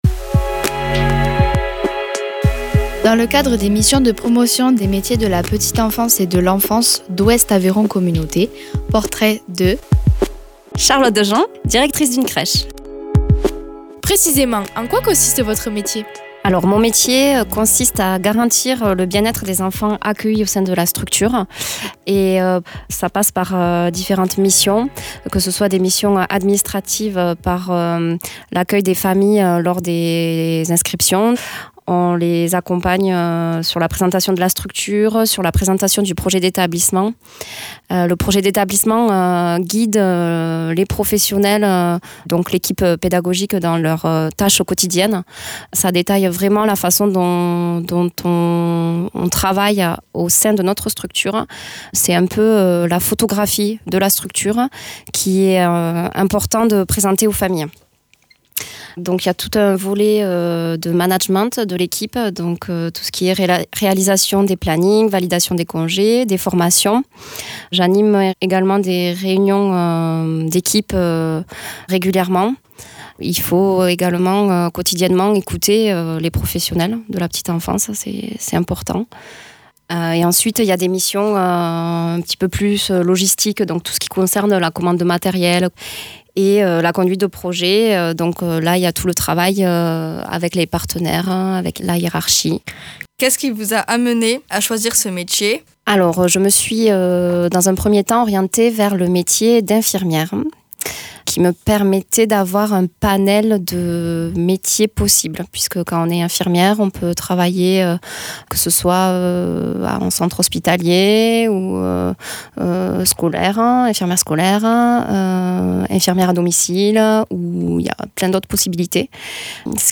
Interviews
Des interviews réalisées dans nos studios.